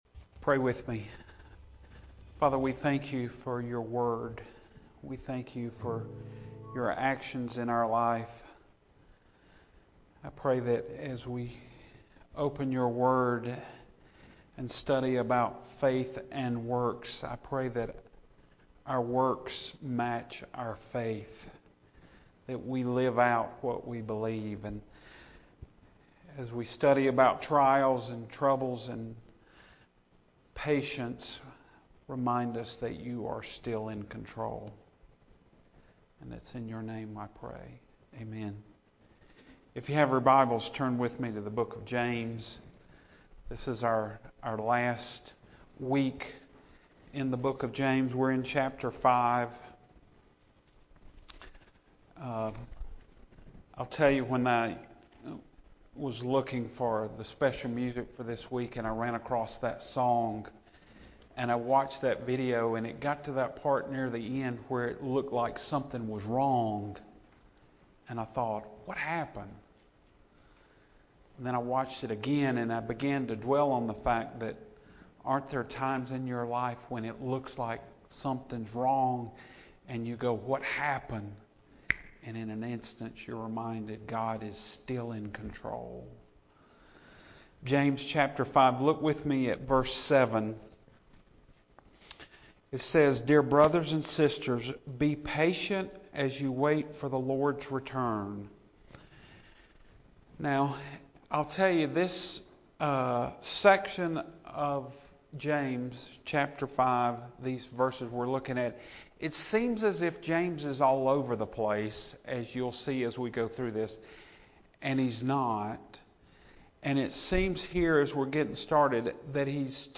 Sermon Only